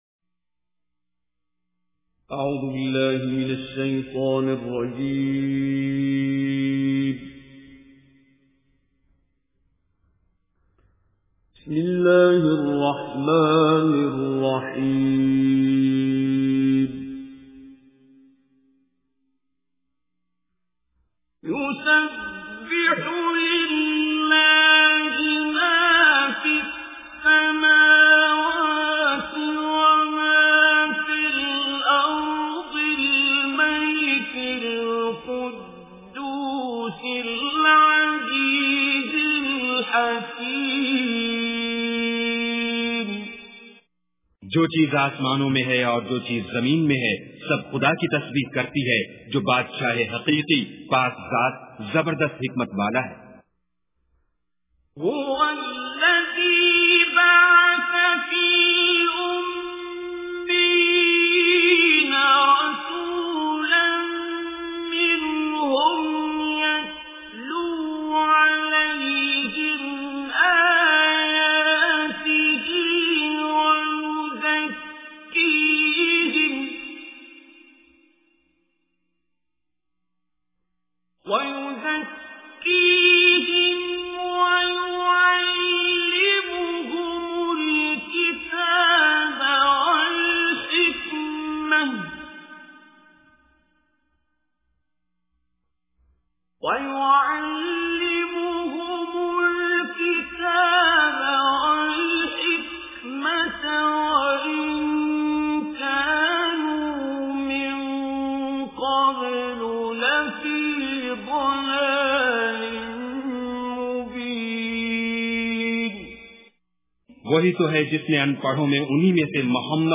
Surah Jumuah Recitation with Urdu Translation
Listen online and download beautiful Quran tilawat / recitation of Surah Jumuah in the beautiful voice of Qari Abdul Basit As Samad.